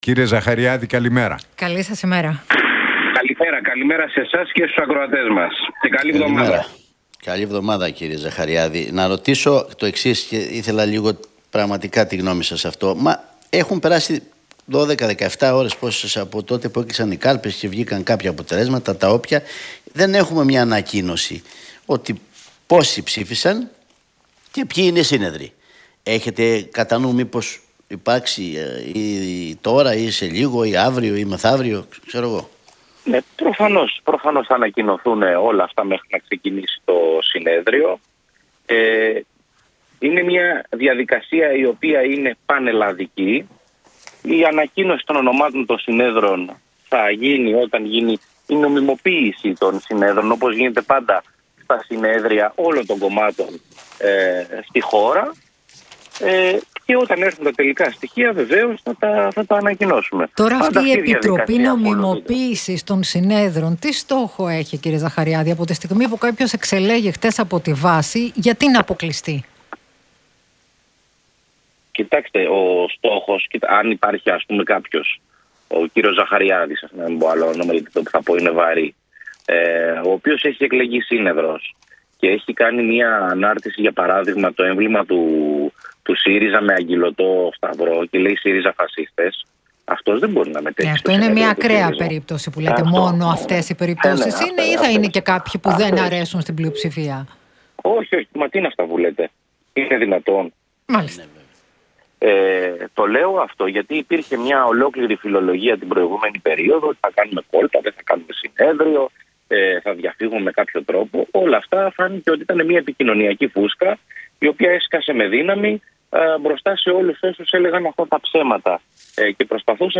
Κώστας Ζαχαριάδης στον Realfm 97,8: Έχει πάρει την απόφασή του ο Στέφανος Κασσελάκης να φτιάξει το κόμμα του